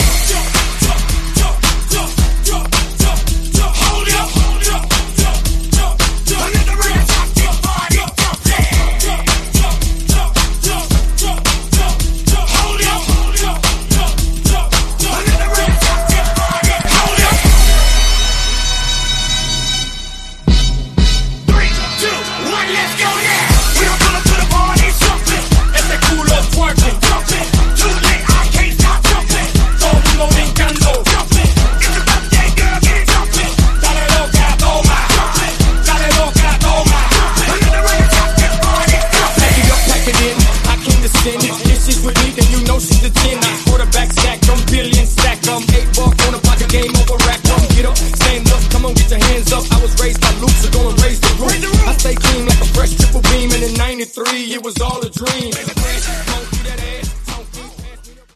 Dj Intro Outro
Genres: 80's , DANCE , RE-DRUM
Clean BPM: 120 Time